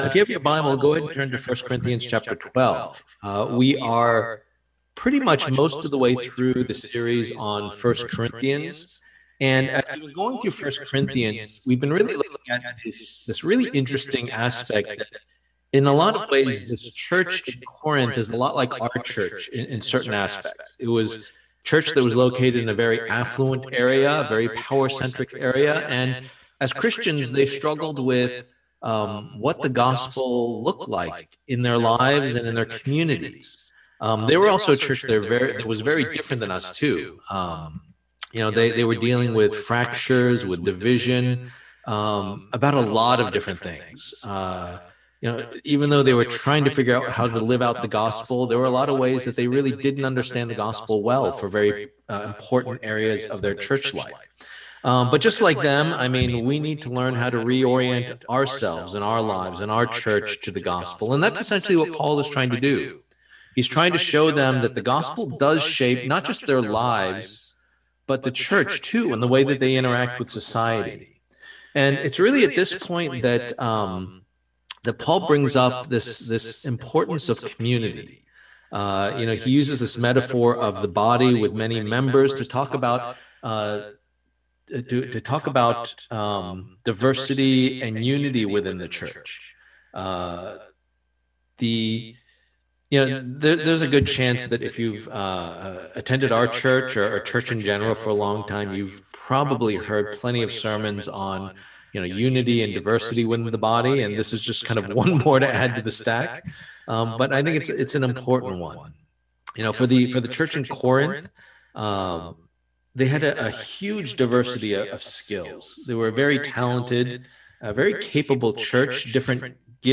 Passage: 1 Corinthians 12:12-31 Service Type: Lord's Day